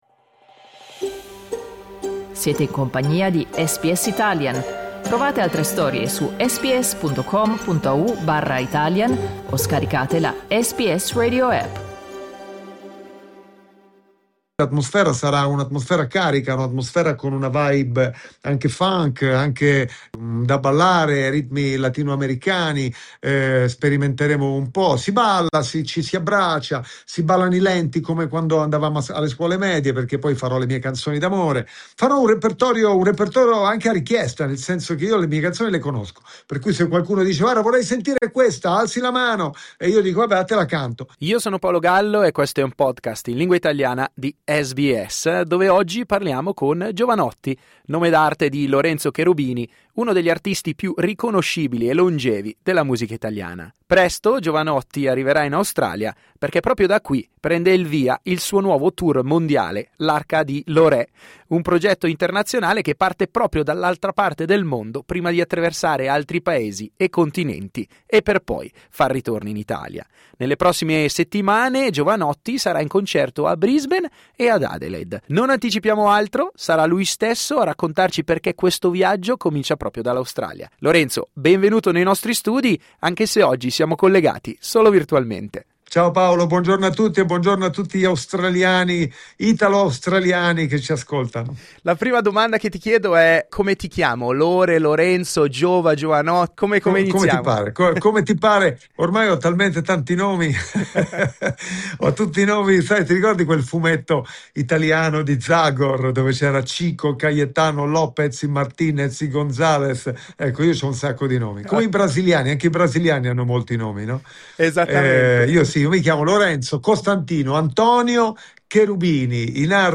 Jovanotti, al secolo Lorenzo Cherubini, si esibirà per la prima volta in Australia all'inizio di marzo. L'abbiamo raggiunto in Italia per una chiacchierata sul suo percorso musicale e per un'anticipazione sul nuovo tour.